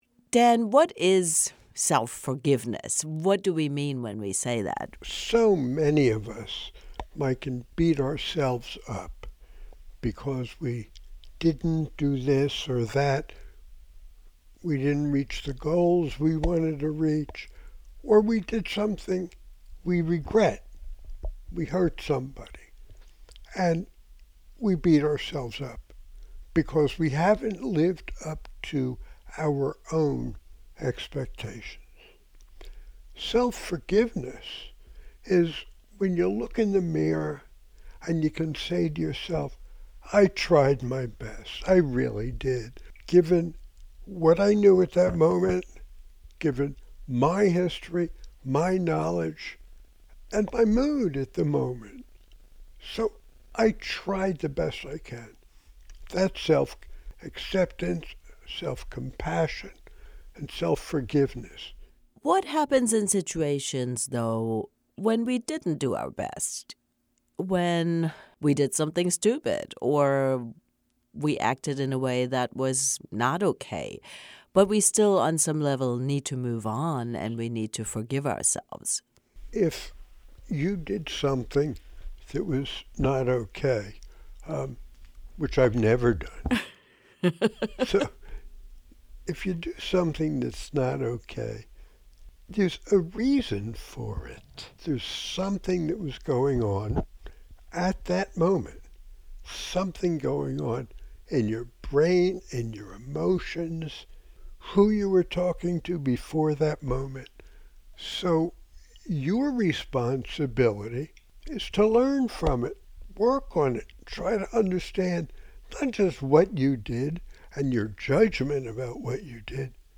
conversations